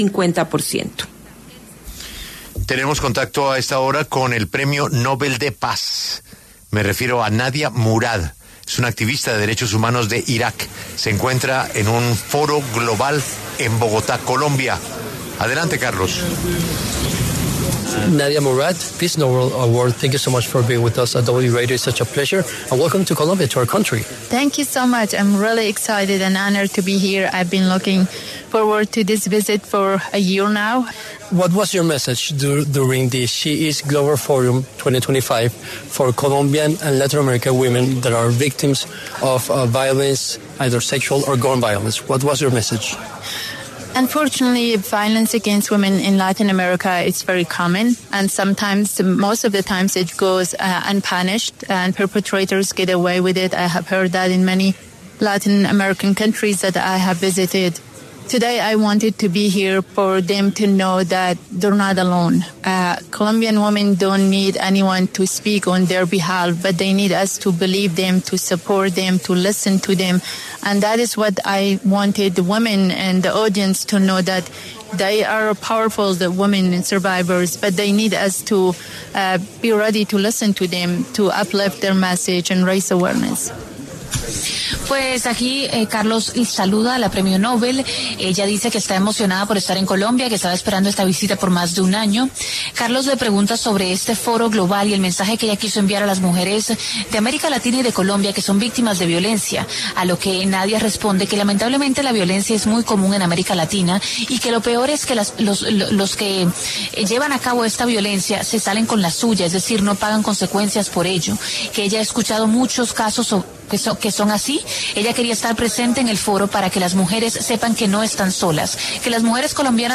Nadia Murad, Nobel de Paz, habló en La W haciendo un llamado a las mujeres latinas que son actualmente víctimas de violencia, animándolas a no dejar que la impunidad sea una regla.